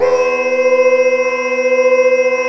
机器人声乐 " 合成歌声音符C 变奏3
Tag: 唱歌 声音 合成器 数字 声码器 无TE puppycat 机器人 电子 合成 自动调谐 C